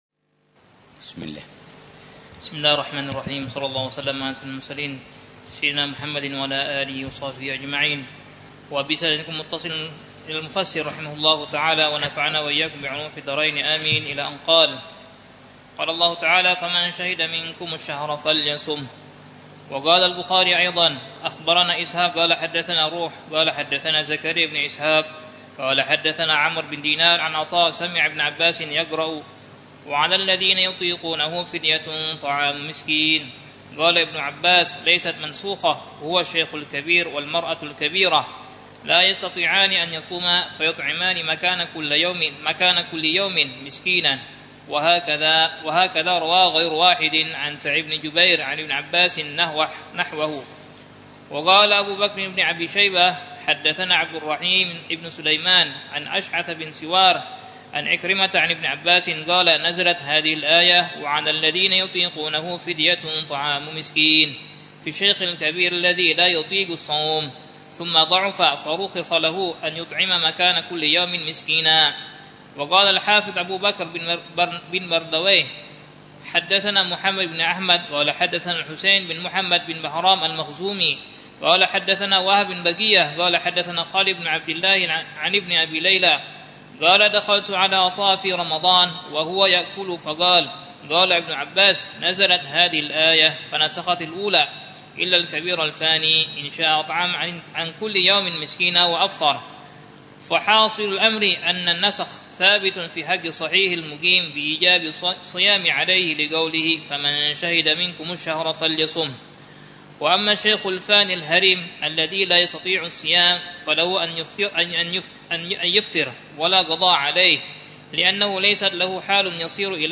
دروس في الروحة الرمضانية التي يقليها الحبيب عمر يومياً بدار المصطفى وتتضمن القراءة في الكتب التالية: تفسير ابن كثير لآيات الصيام، كتاب الصيام